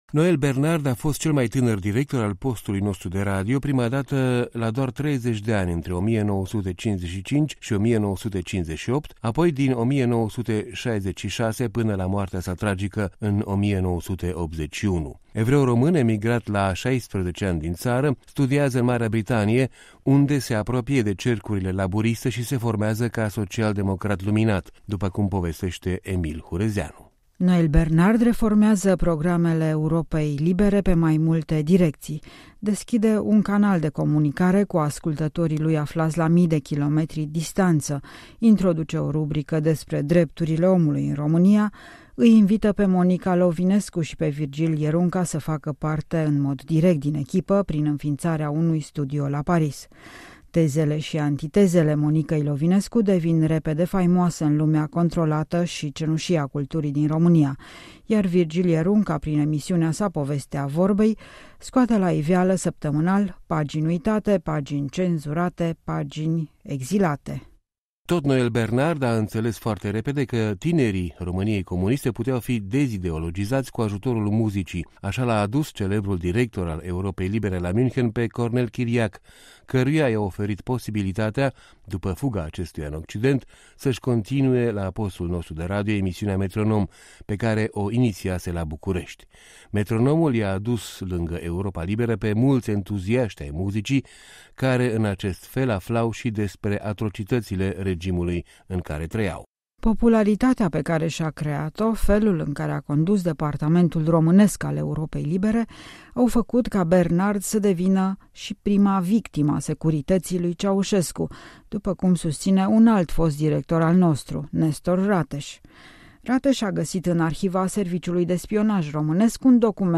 Vorbele rostide de Virgil Ierunca la Europa Liberă în 23 decembrie 1981, la încetarea din viață a directorului postului nostru de radio.